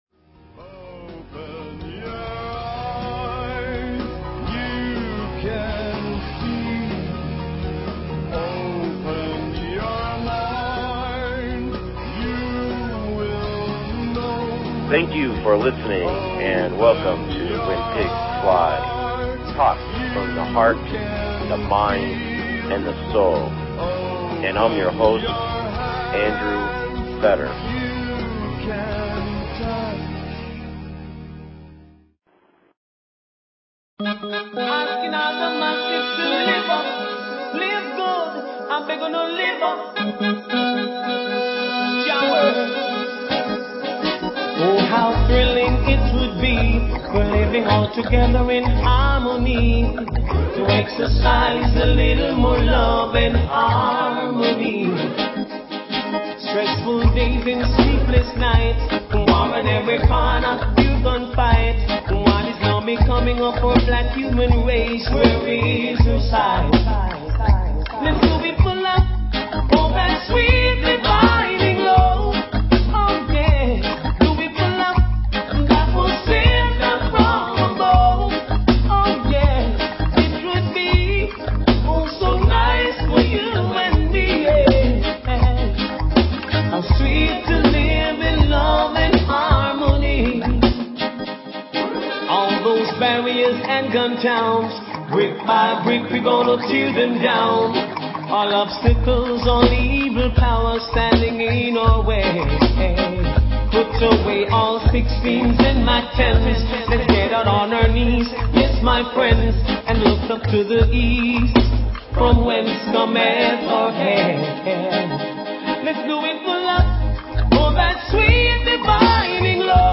Talk Show Episode, Audio Podcast, When_Pigs_Fly and Courtesy of BBS Radio on , show guests , about , categorized as